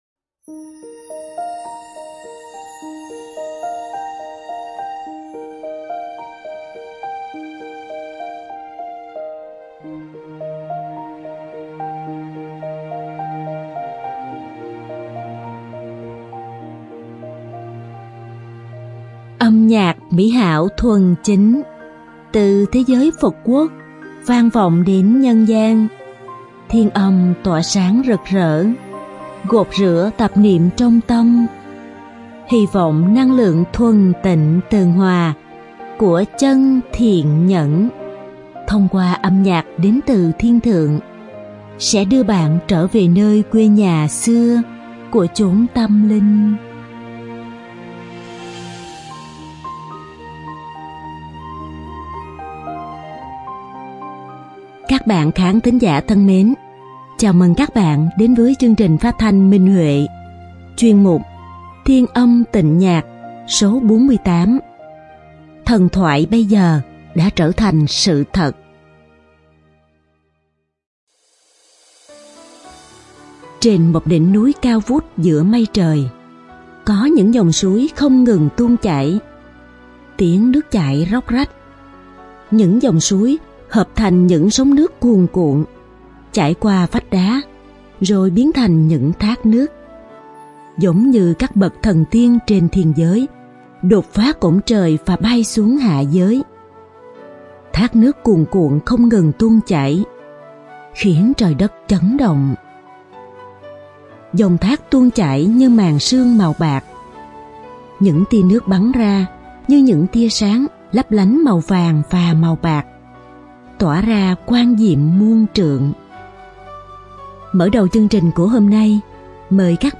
Âm nhạc mỹ hảo thuần chính, từ thế giới Phật quốc vang vọng đến nhân gian, thiên âm tỏa sáng rực rỡ, gột rửa tạp niệm trong tâm, hy vọng năng lượng thuần tịnh